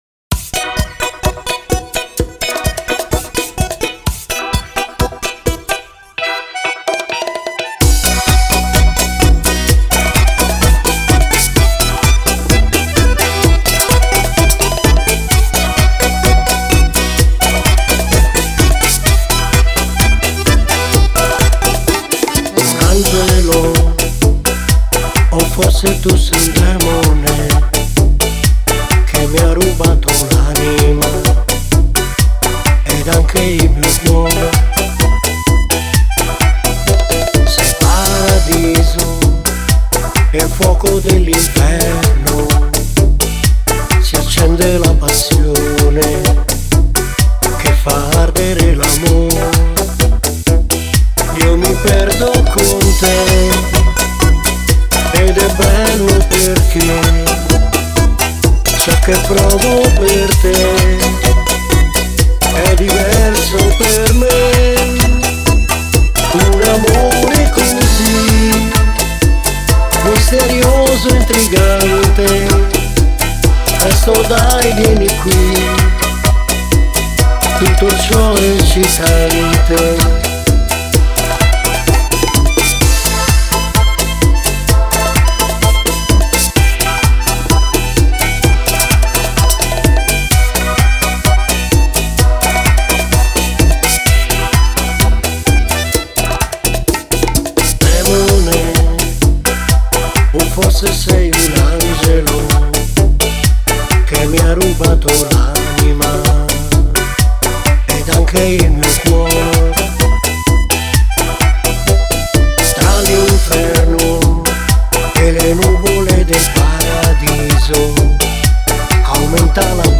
Bachatango
(Play e base karaoke)